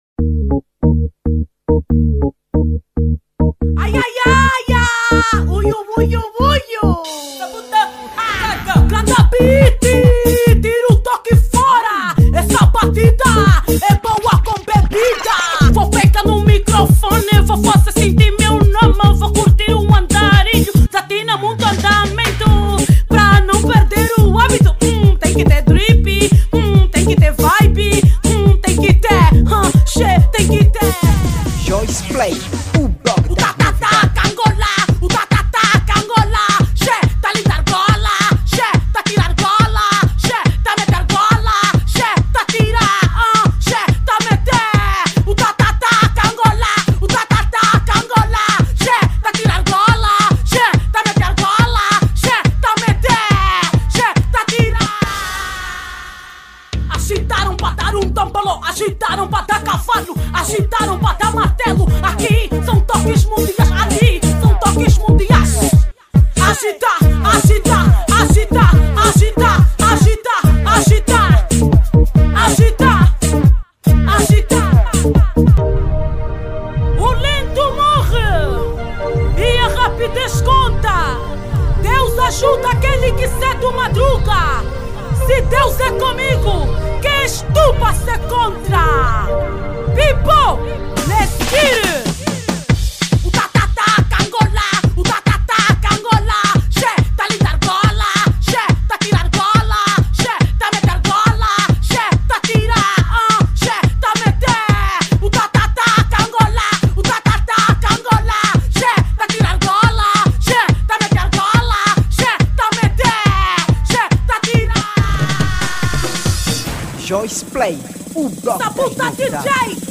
Género: Kuduro Ano de Lançamento